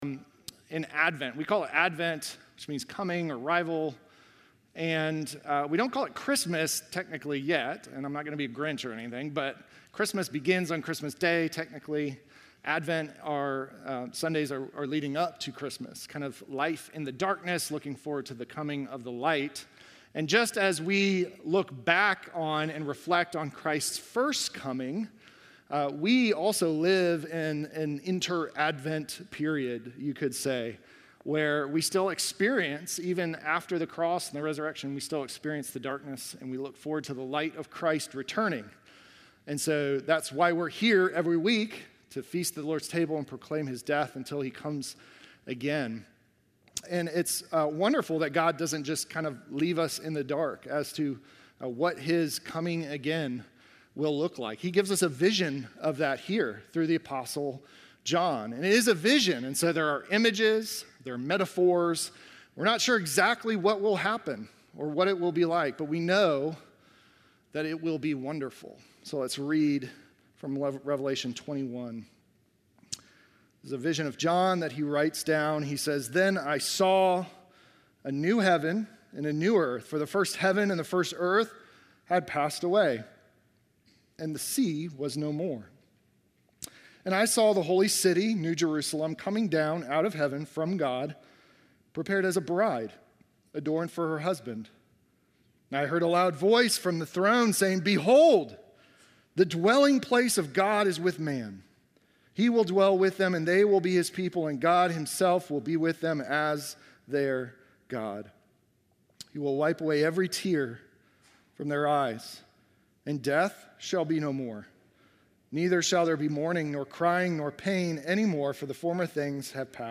Sermon from December 21